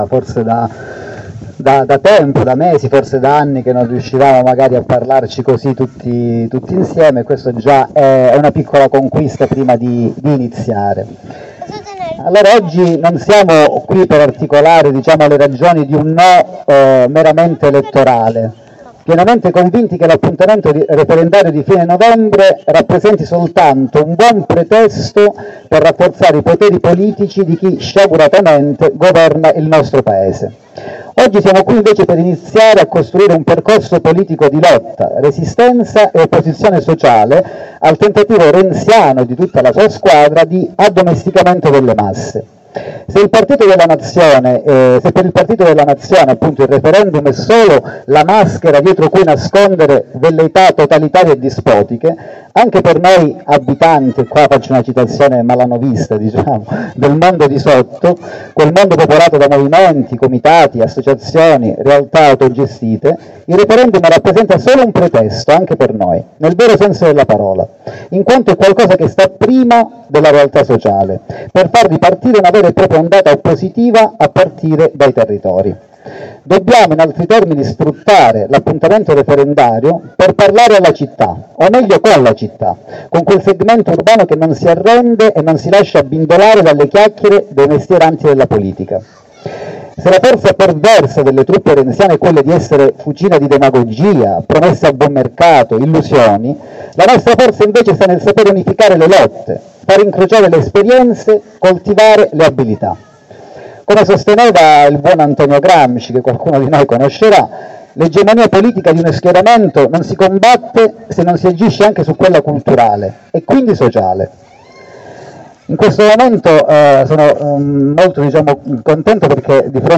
ASSEMBLEA NO SOCIALE: Intervento introduttivo
Pubblichiamo in questa rubrica le parti più interessanti dei diversi interventi fatti durante l’assemblea dell’area urbana sul No Sociale.